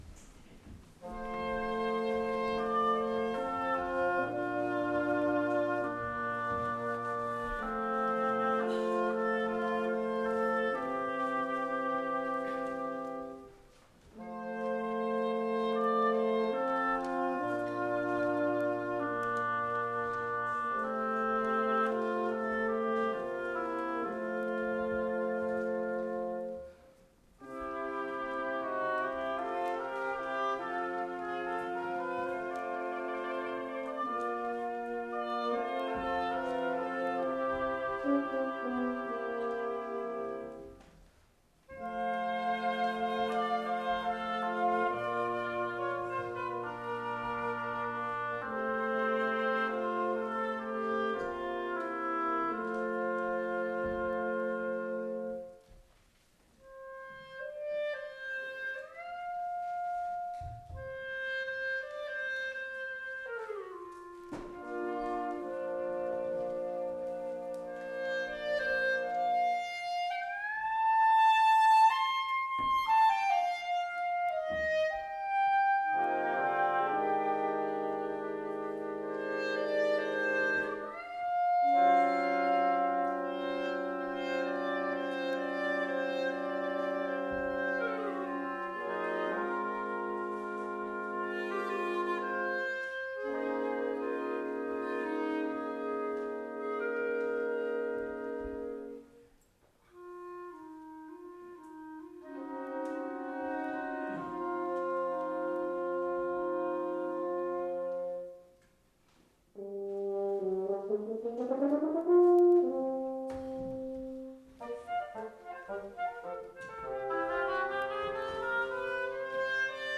for Woodwind Quintet (1988)